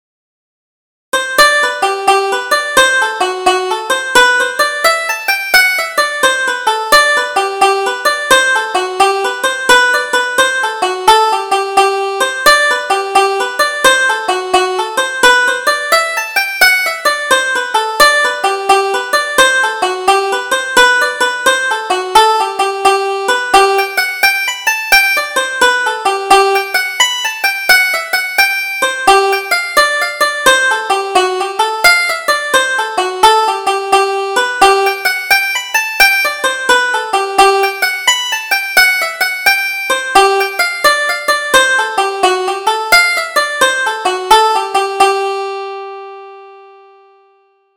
Double Jig: Down the River